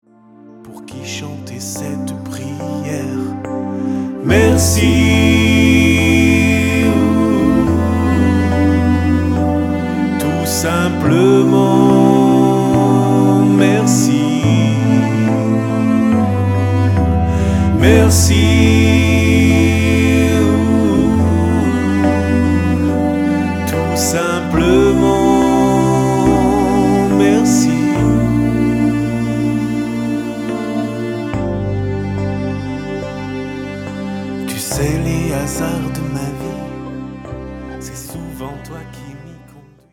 allant du pop au rock en passant par des ballades douces